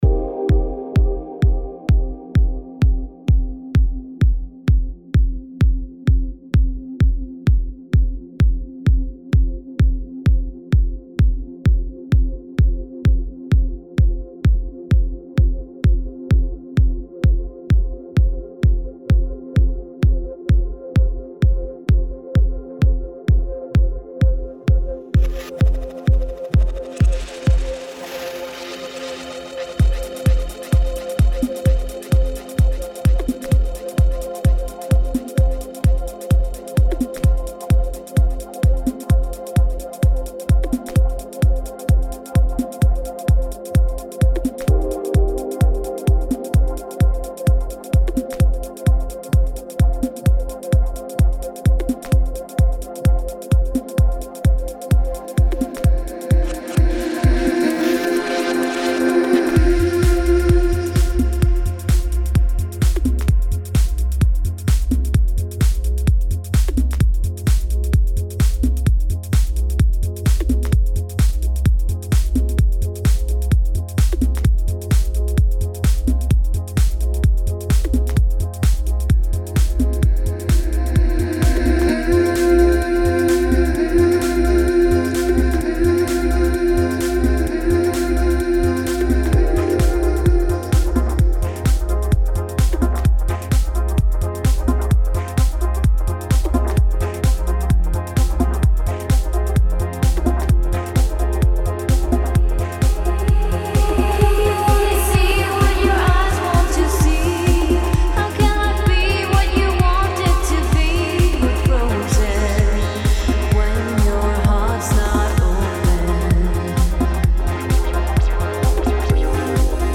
Progressive House - 7:35